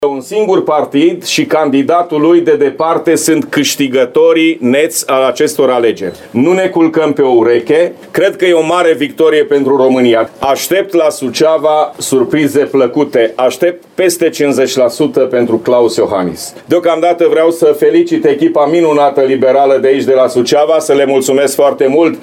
VIDEO / Declarații la ora 21 la sediile PNL și PSD Suceava, după primul tur al alegerilor prezidențiale
La sediul PNL, președintele GHEORGHE FLUTUR a declarat că victoria în primul tur a președintelui IOHANNIS arată popularitatea de care se bucură atât în țară, cât și în diaspora.